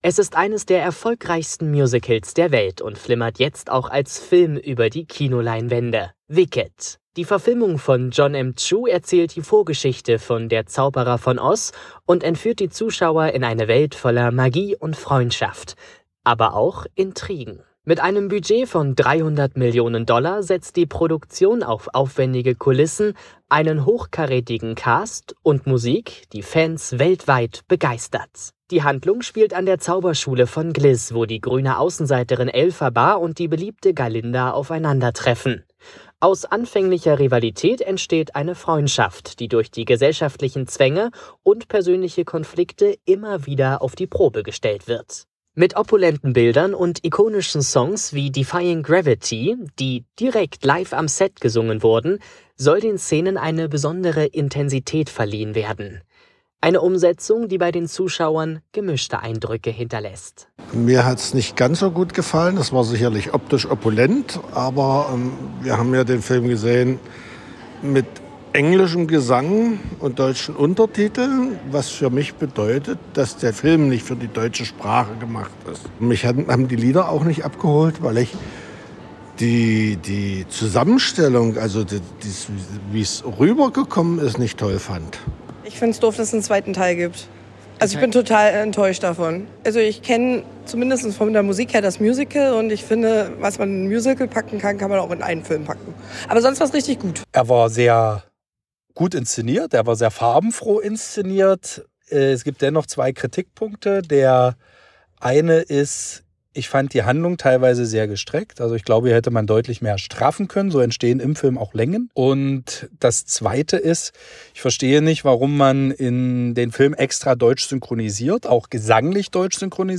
Film-Rezension: Wicked